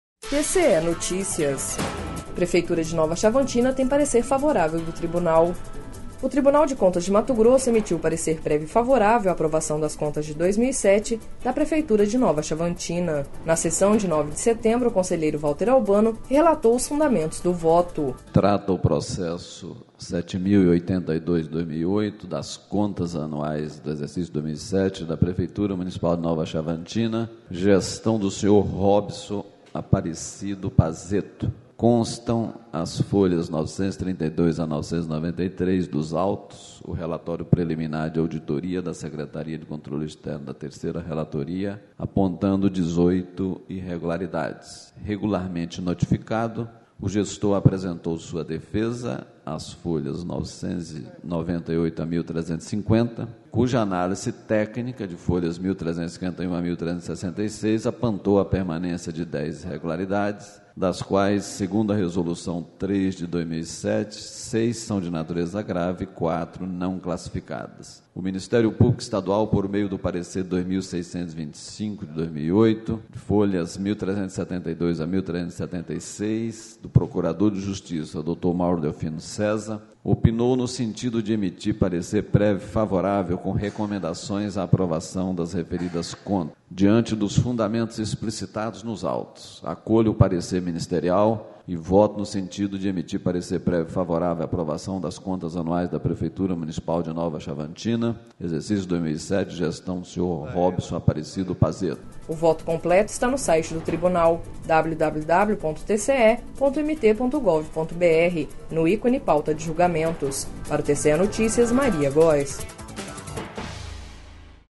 Sonora: Valter Albano - conselheiro presidente do TCE-MT